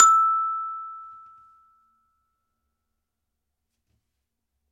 风铃 " 风铃01 d3 01
:) 录制了Zoom H5和Rode NT2000.Edited Audacity和ocenaudio。
标签： 记录 钟琴 单票据 WAV 铁琴 样品包 坎贝奈 打击乐器 样品 金属 多重采样 请注意 多重采样 一次性
声道立体声